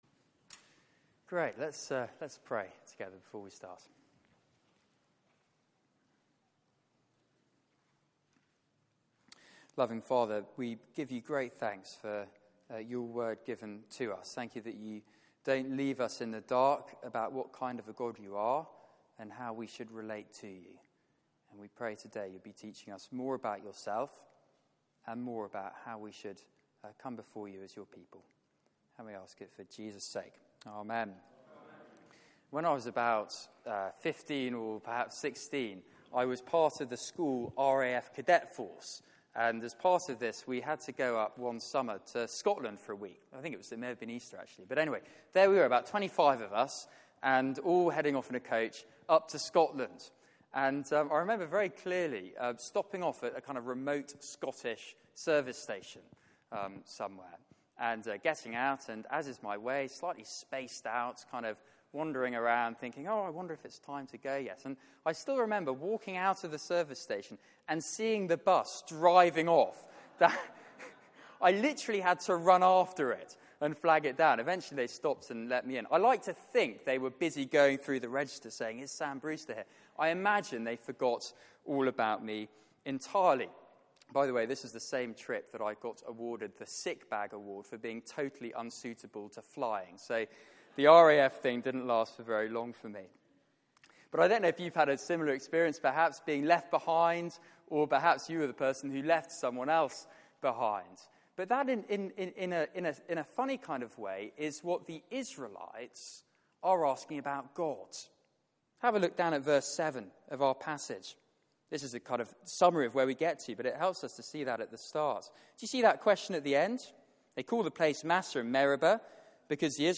Passage: Exodus 17:1-7 Service Type: Weekly Service at 4pm